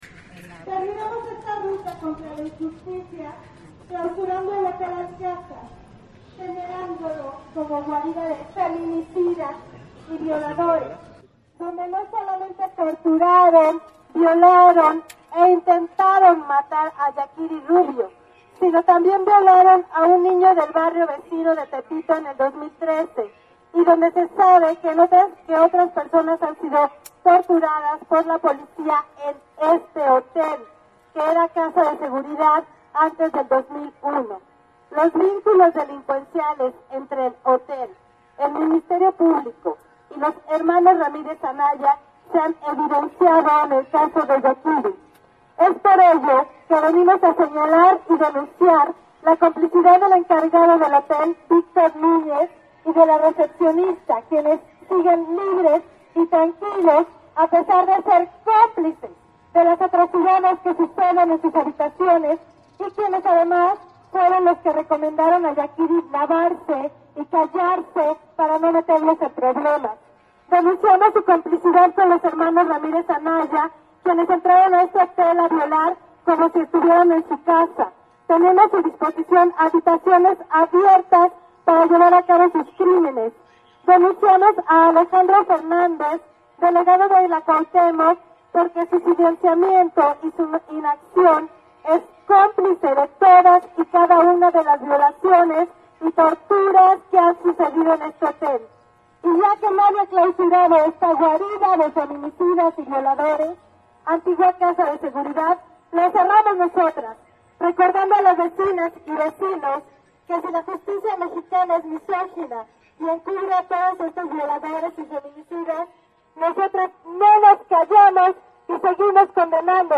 Hotel Alcázar Clausurado. Acción por la clausura del hotel Alcazar, lugar que hasta 2001 fue casa de seguridad, y donde ahora se ejercen violencias, abusos, torturas por parte de la policía, con la complicidad del Ministerio Publico